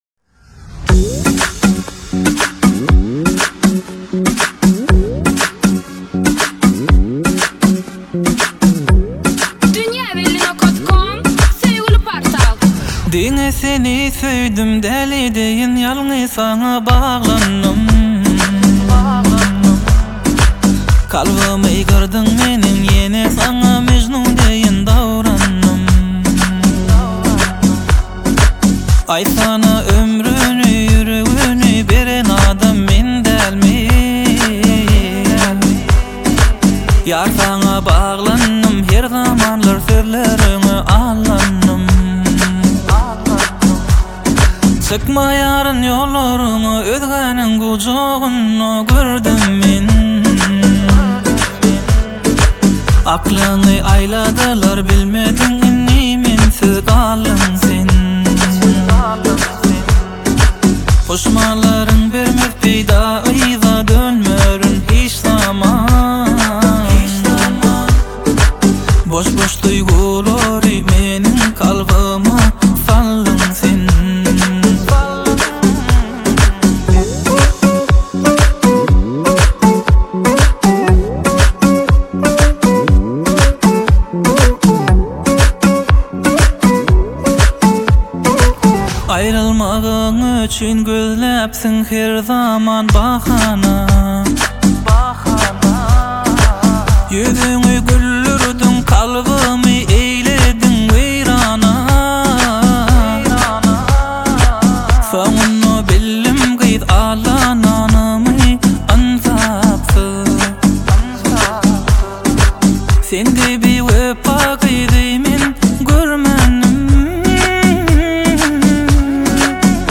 Bölüm: Aydym / Türkmen Aýdymlar